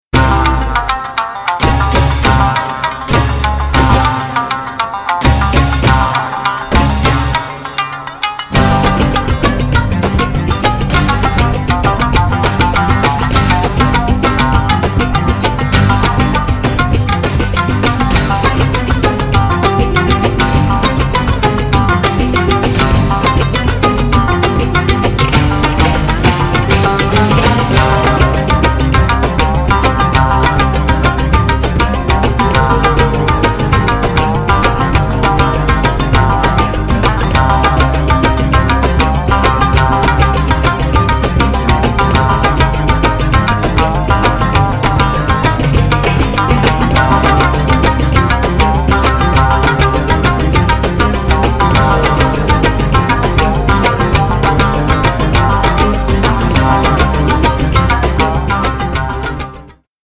無限とも思えるほどの奥深い日本文化の神髄を洋楽器と共に表現し、日本人のDNAの中にある「和の心」を震わせる一枚。